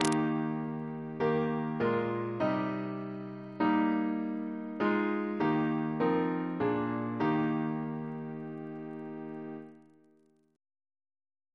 Single chant in E♭ Composer: Edward John Hopkins (1818-1901), Organist of the Temple Church Reference psalters: ACB: 83; OCB: 54; PP/SNCB: 73; RSCM: 185